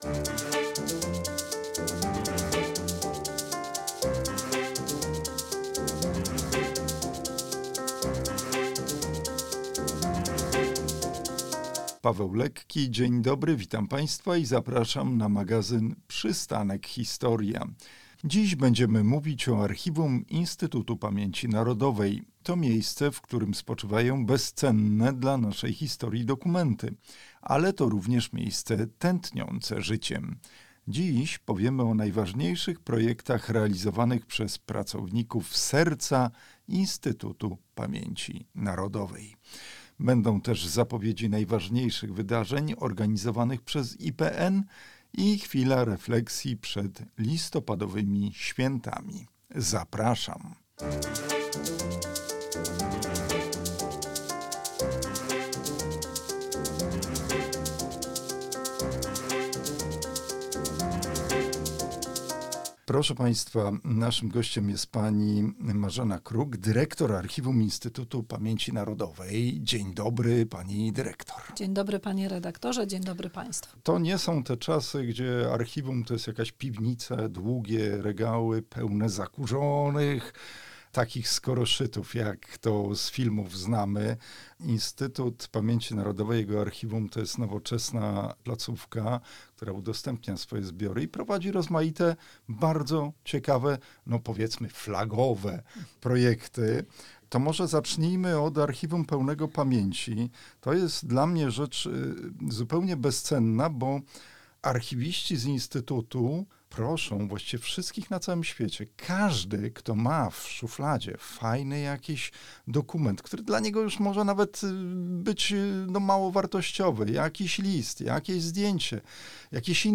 Naszym gościem jest dyrektor Archiwum IPN Marzena Kruk.